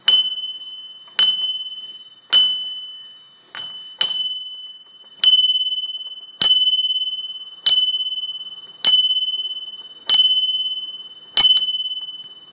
Nástenný zvonček so zelenou patinou liatina 17x14cm
Nástěnný zvonek
nastenny-zvonek.m4a